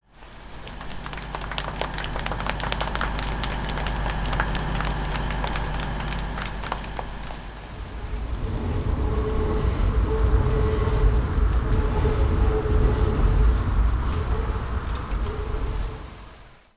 Sound Design
The sound design of this section symbolizes the repeated attempts and failures in psychology through a looping rhythm and a faint modulated melody.